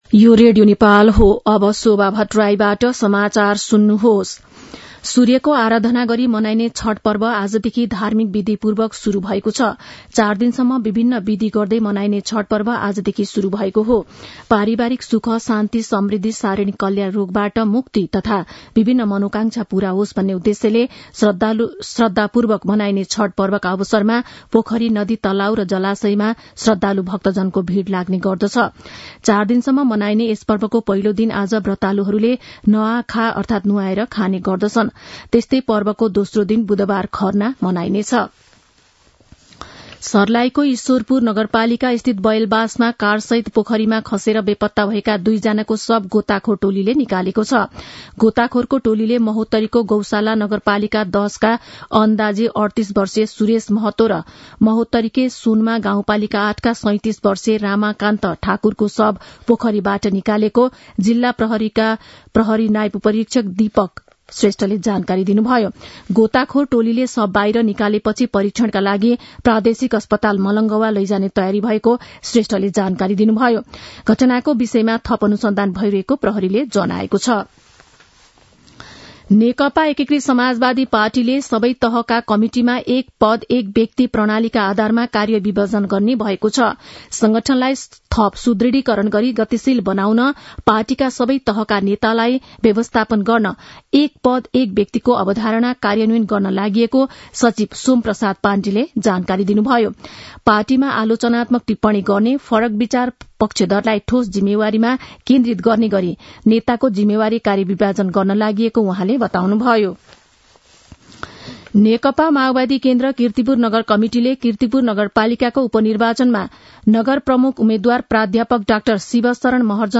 मध्यान्ह १२ बजेको नेपाली समाचार : २१ कार्तिक , २०८१
12-am-news.mp3